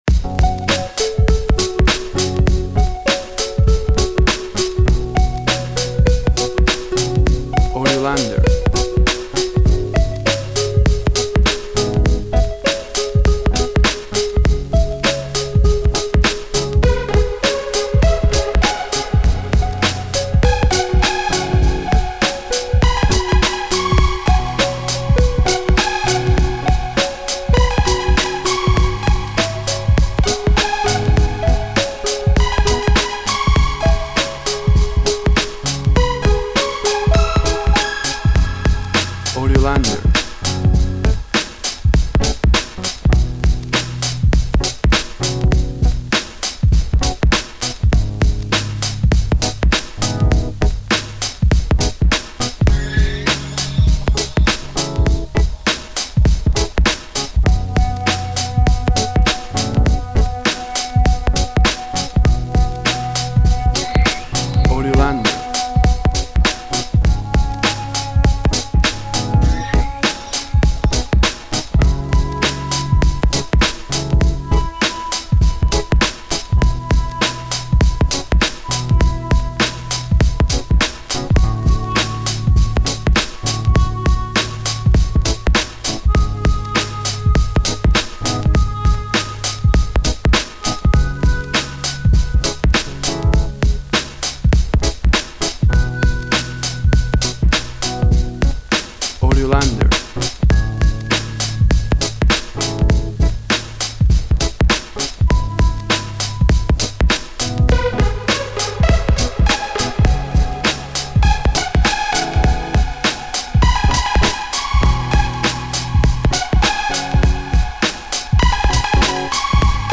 Energetic musical fragment to different environments.
Tempo (BPM) 90